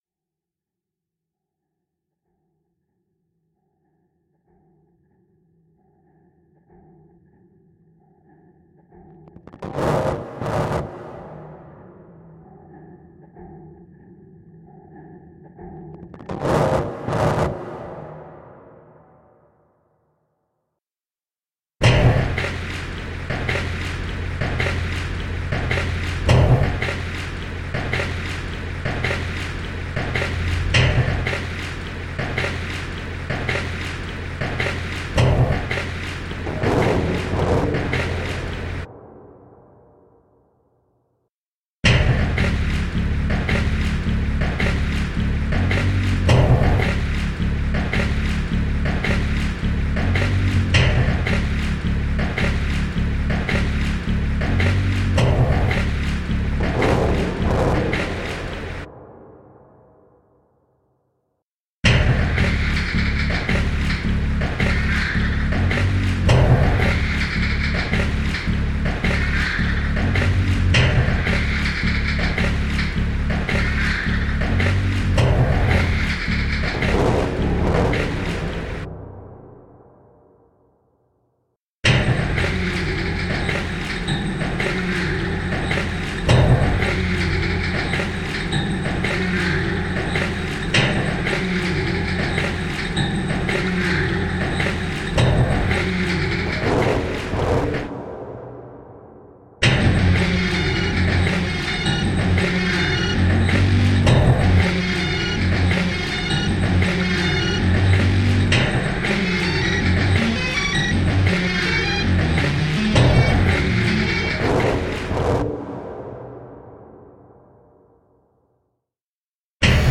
remixes the sound of the Landungsbruecken